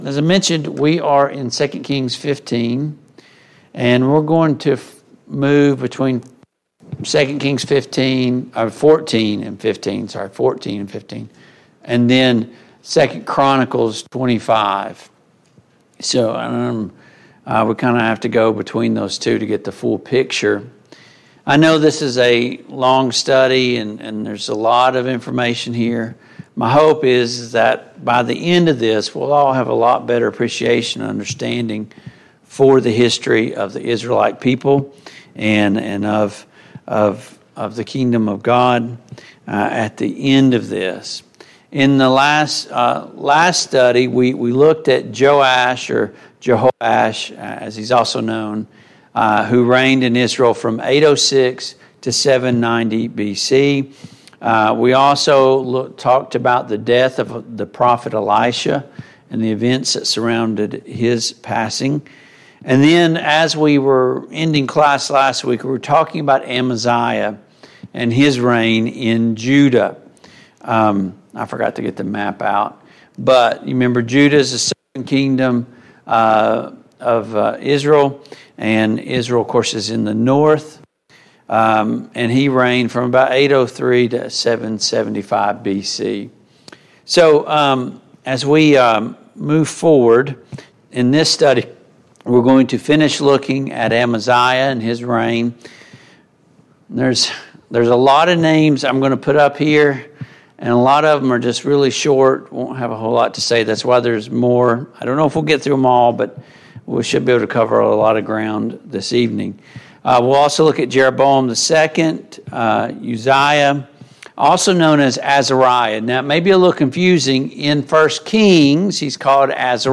The Kings of Israel and Judah Passage: 2 Kings 14, 2 Kings 15, 2 Chronicles 25, 2 Chronicles 26 Service Type: Mid-Week Bible Study